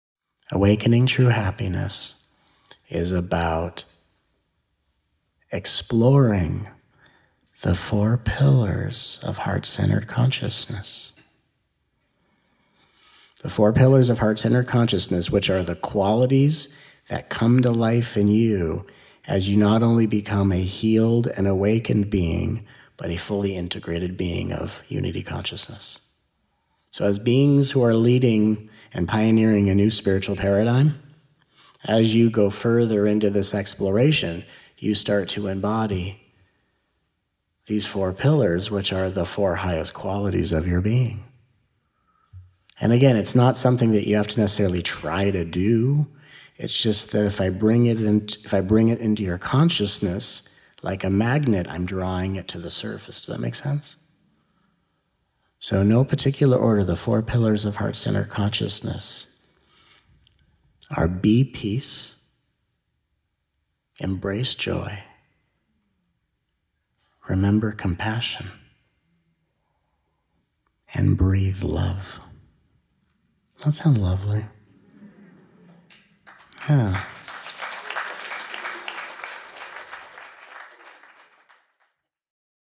If so, join us for these 14 hours of nourishing, uplifting, and transformative highlights from the miraculous 5-day retreat.
Some teachings came through in quiet, pin-drop, palpable loving kind of energy, while other teachings went deep and inspired uproarious and hilarious laughter and insights that will shake you free.